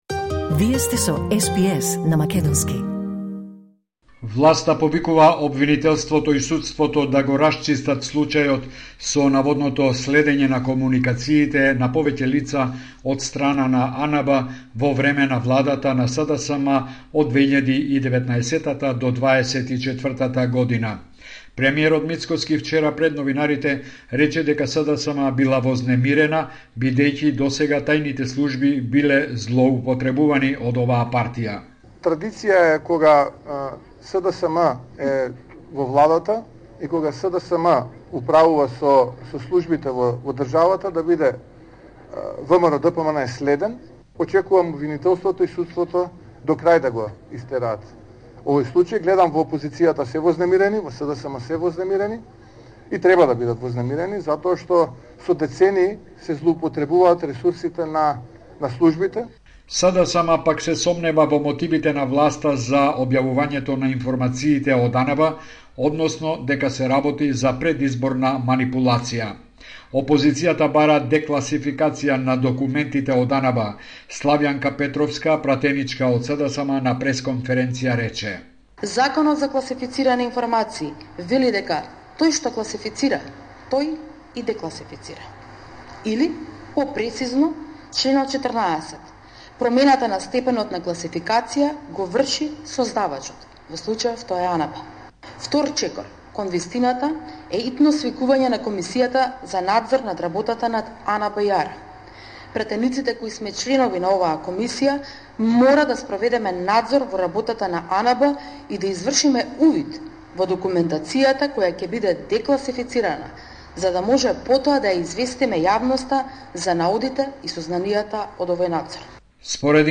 Извештај од Македонија 5 септември 2025